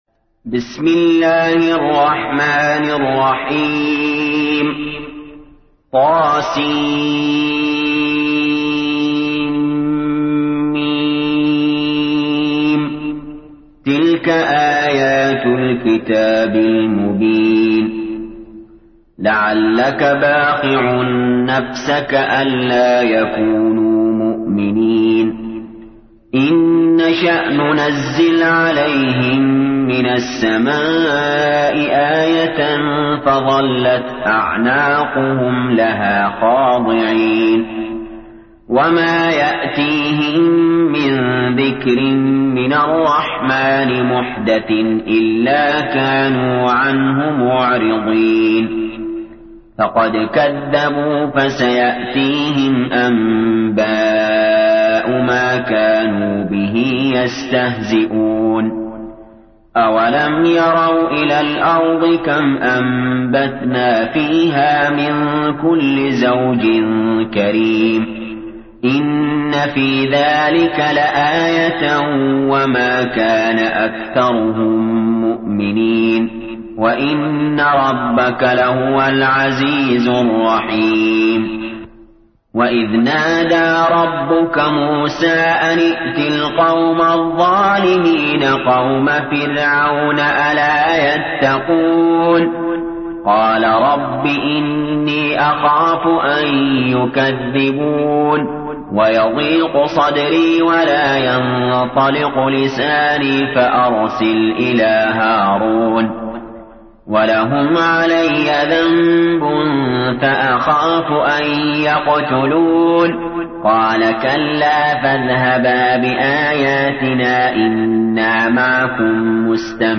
تحميل : 26. سورة الشعراء / القارئ علي جابر / القرآن الكريم / موقع يا حسين